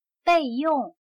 备用/Bèiyòng/Reservar, guardar algo para usarse en el futuro.